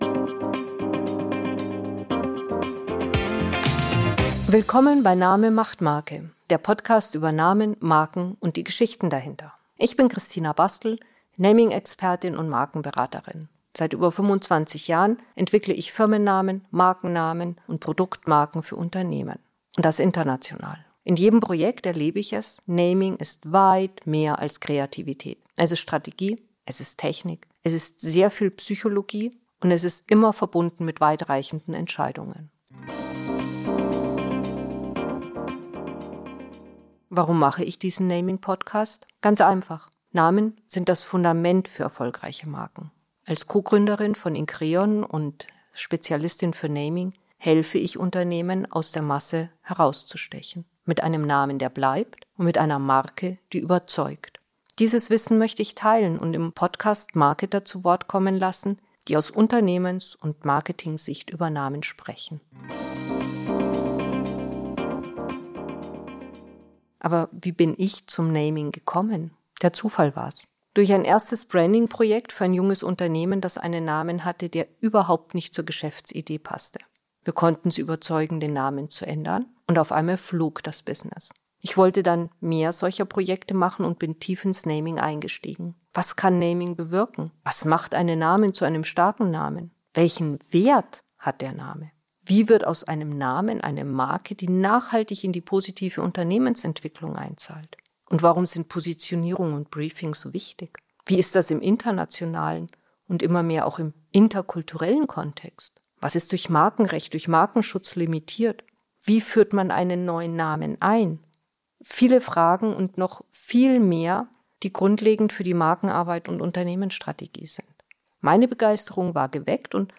In „Name.Macht.Marke.“ spreche ich mit Marketern über Markennamen ihre Entwicklung. Unscripted.